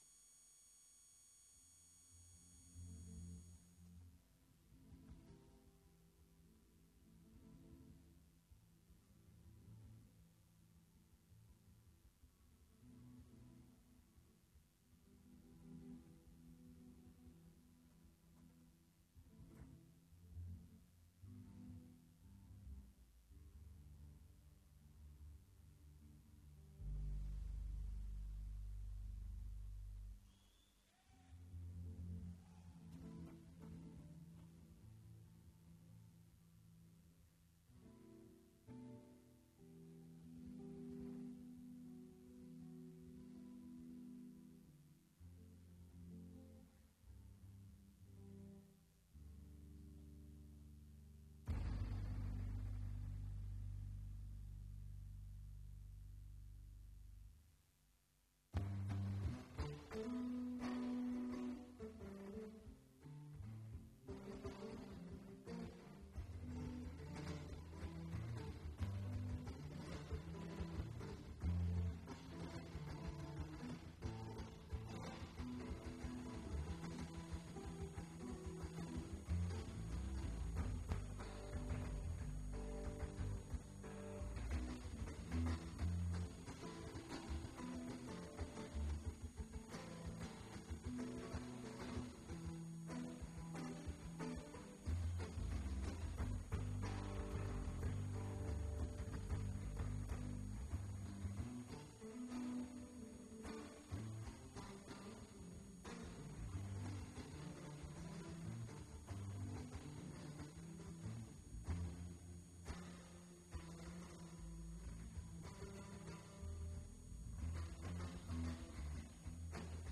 venue Royal Festival hall